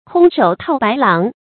空手套白狼 kōng shǒu tào bái láng 成语解释 徒手抓住白狼。